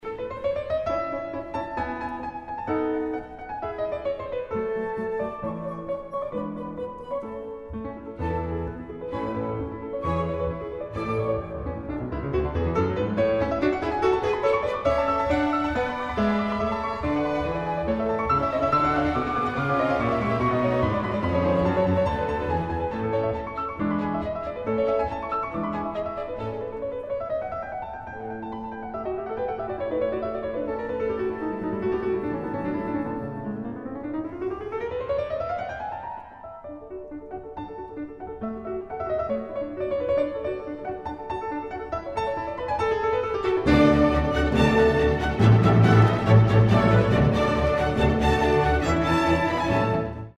Allegro di molto